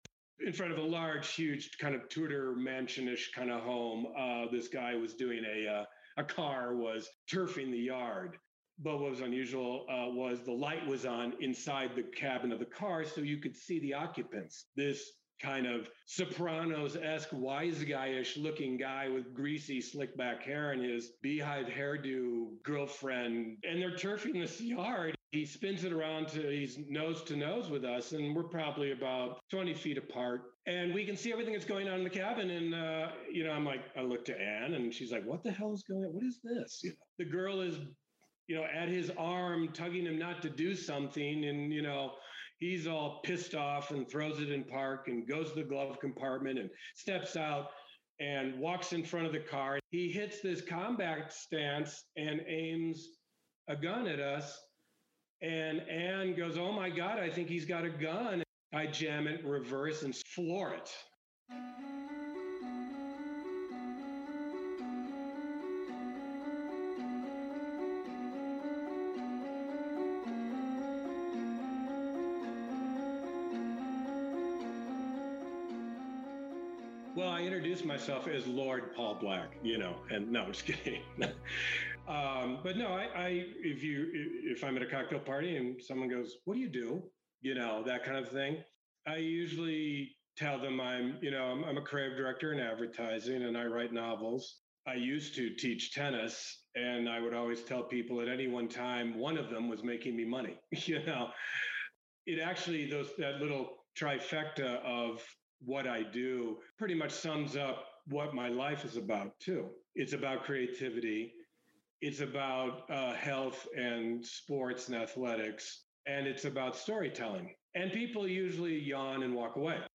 In this longer interview, listen to the story of how he saved both he and his date's lives.